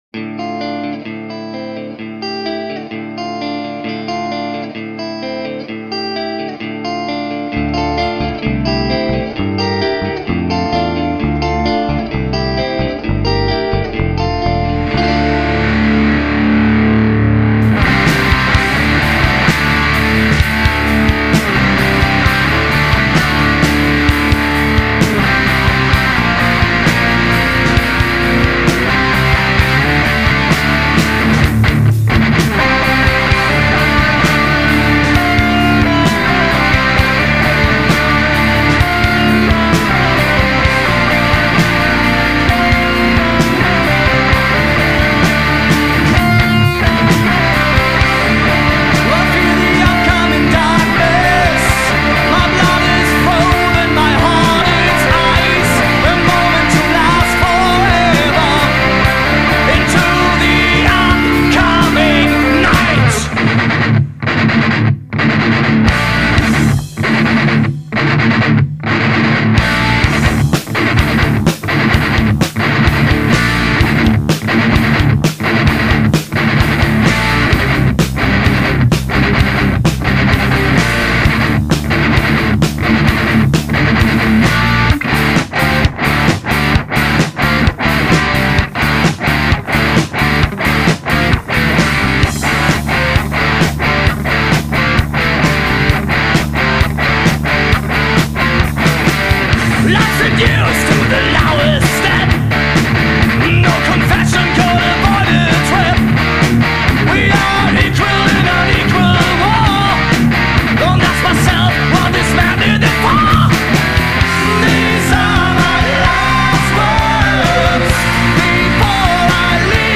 Studioaufnahmen